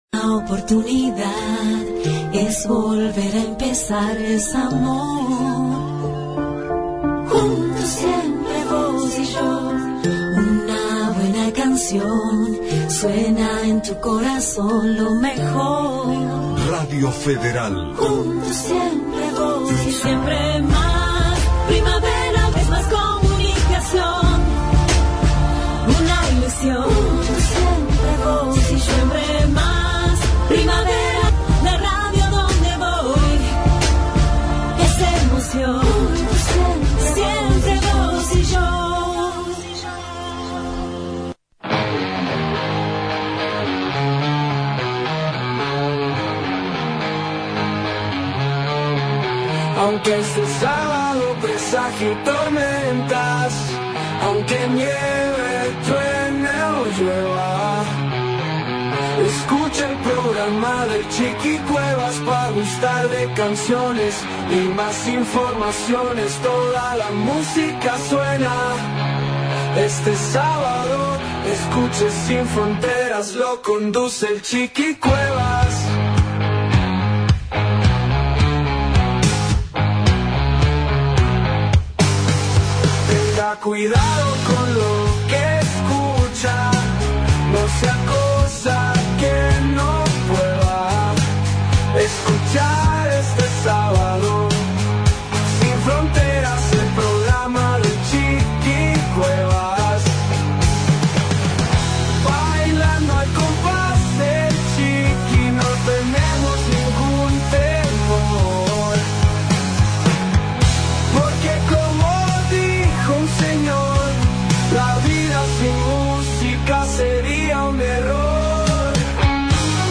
MUSICA - Rock Clásicos e Internacionales